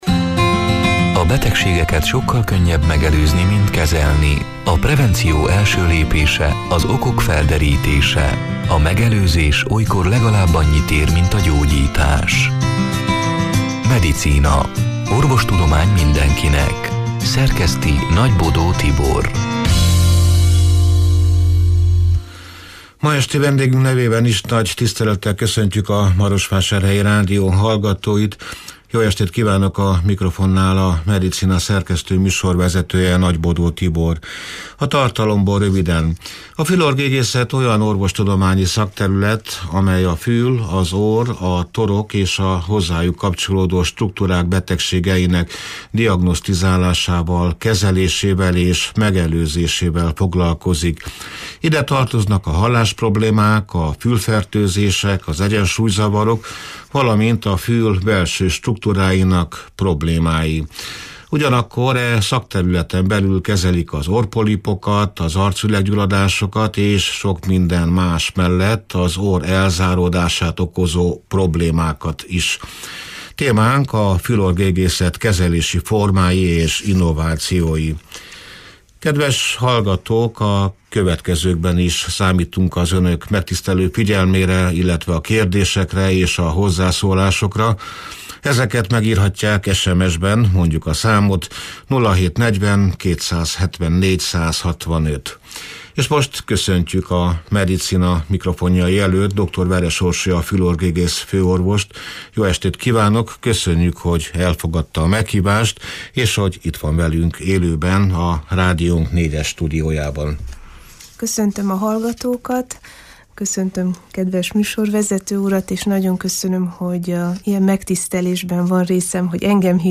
A Marosvásárhelyi Rádió Medicina (elhangzott: 2025. március 5-én, szerdán este nyolc órától élőben) c. műsorának hanganyaga: A fül-orr-gégészet olyan orvostudományi szakterület, amely a fül, az orr, a torok és a hozzájuk kapcsolódó struktúrák betegségeinek diagnosztizálásával, kezelésével és megelőzésével foglalkozik.